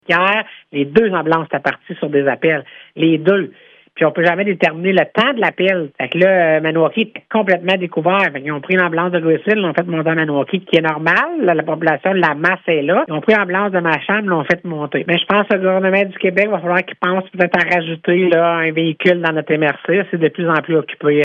La préfète Lamarche affirme qu’il est essentiel d’augmenter le nombre d’ambulances desservant la région :